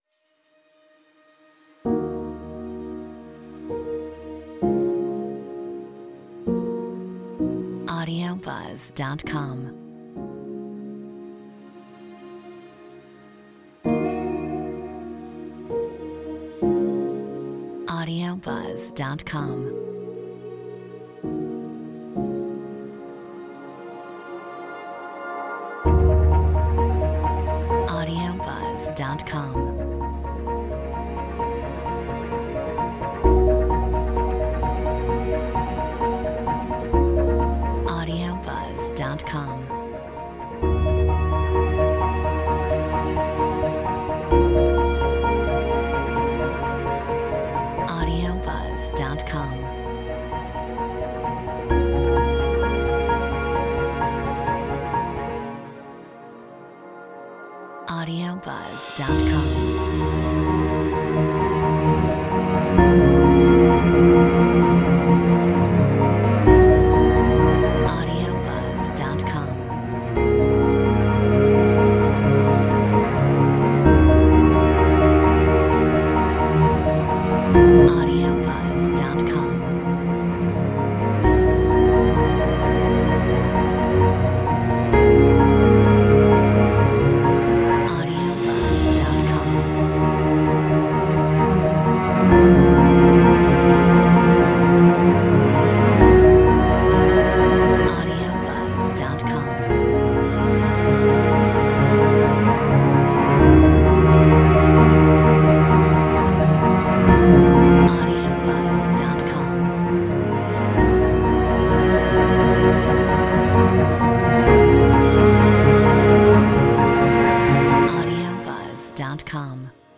Metronome 65 BPM
Cinematic Drama Production / Film Scores